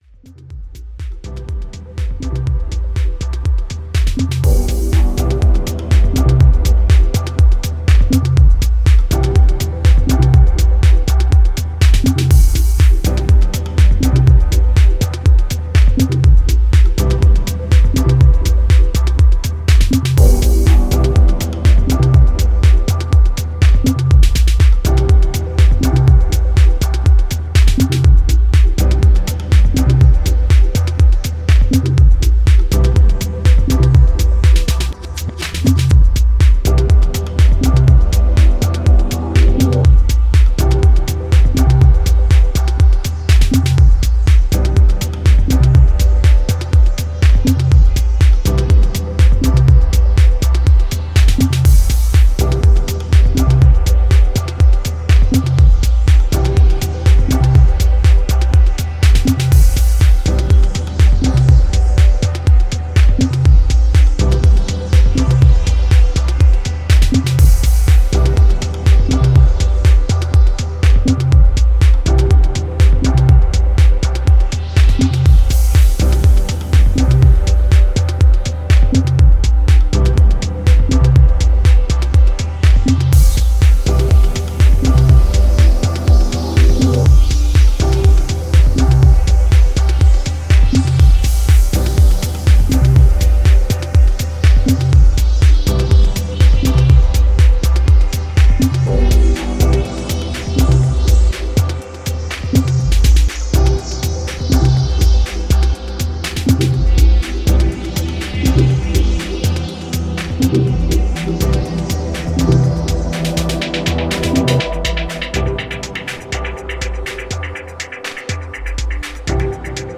filigree and atmospheric journey